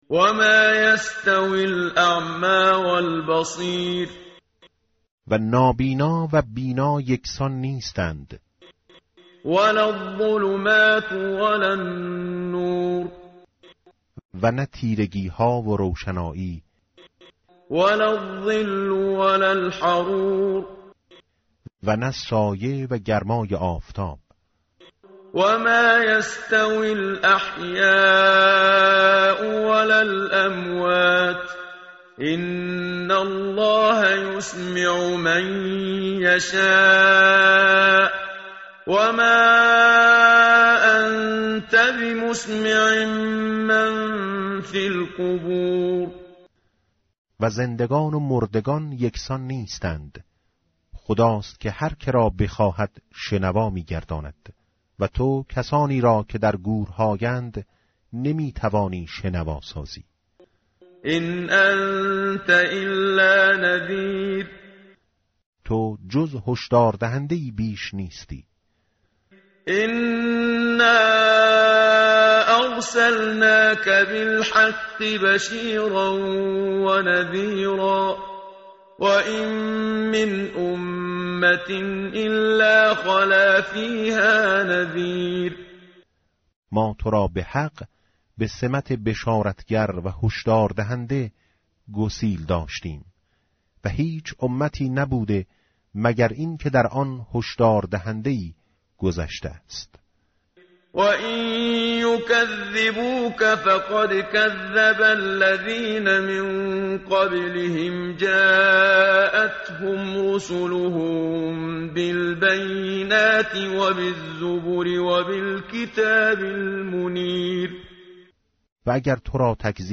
tartil_menshavi va tarjome_Page_437.mp3